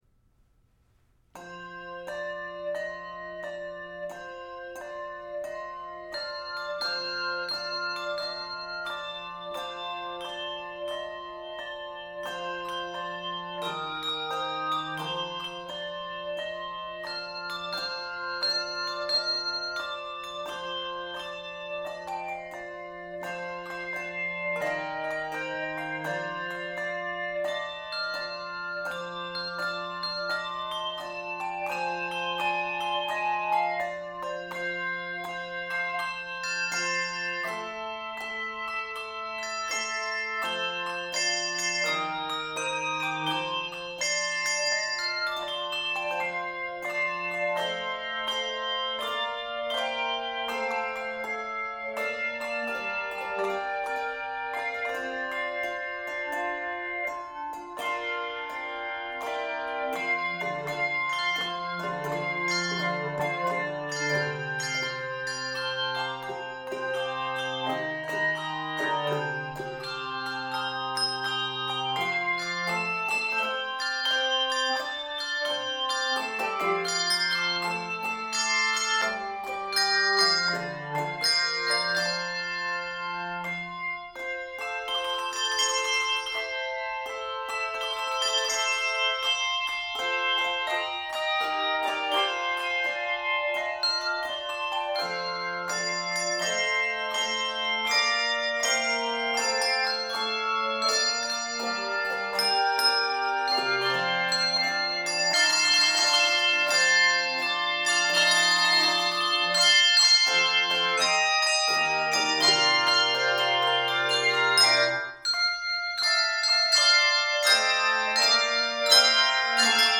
Your ringers will love playing this bluesy
African American spiritual